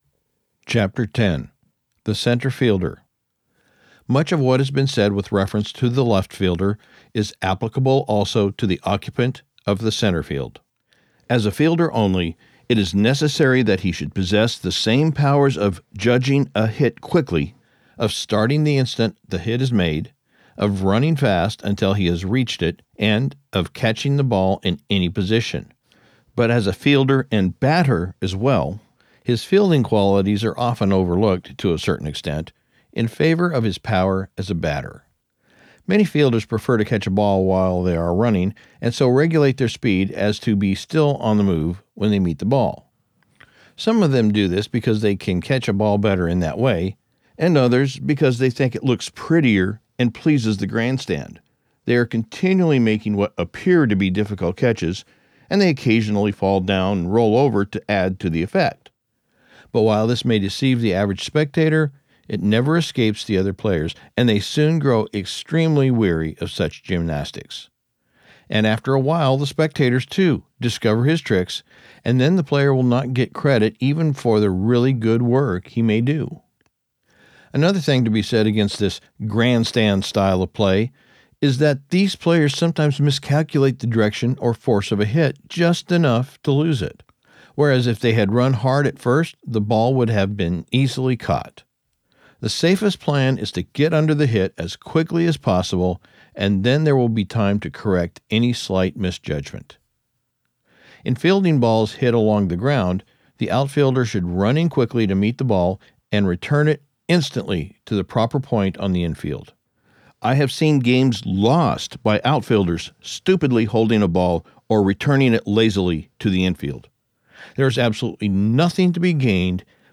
Audiobook production Copyright 2025, by GreatLand Media